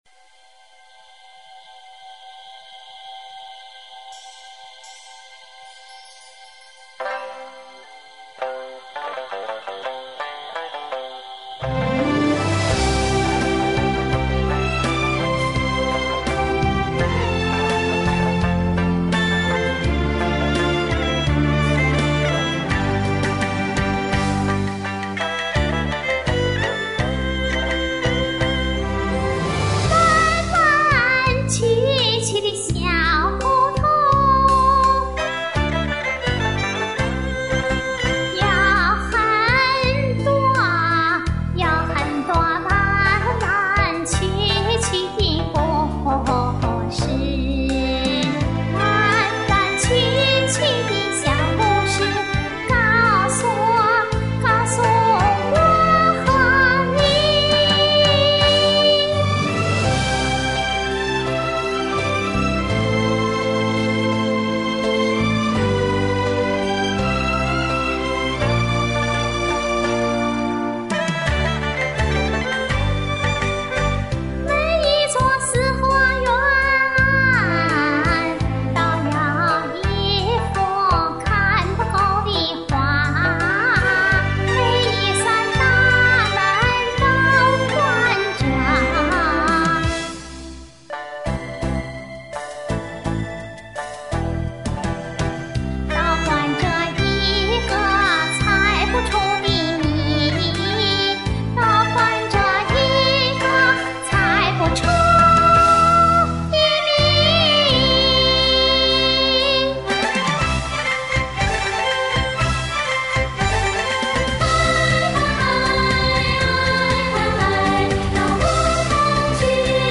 还有就是上气不接下气,特别明显.还扯着嗓子喊.好象调对我来说也太高.